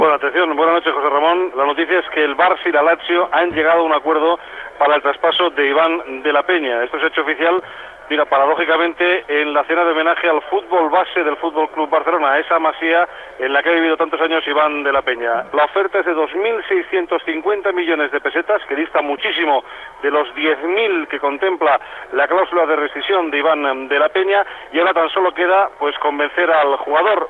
Declaració del jugador.
Esportiu